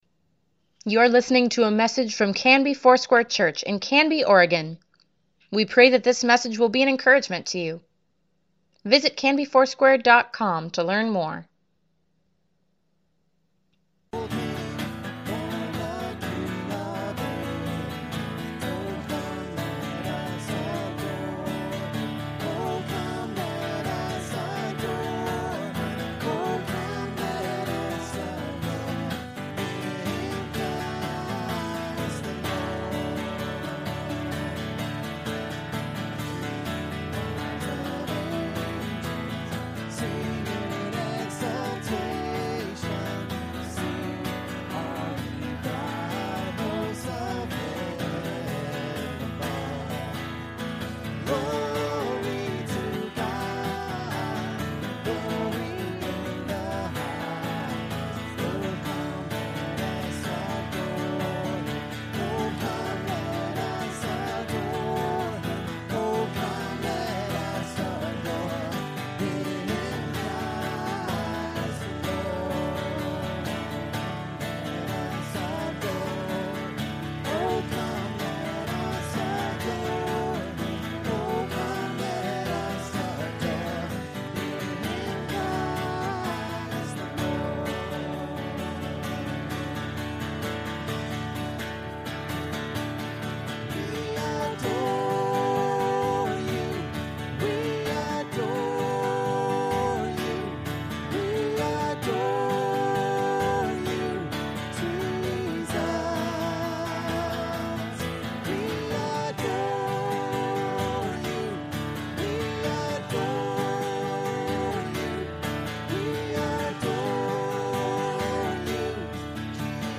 Our entire Christmas Eve service is here for you to enjoy.